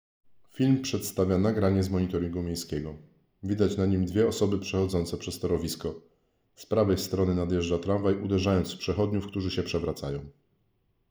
Nagranie audio Audiodeskrypcja_do_filmu.mp3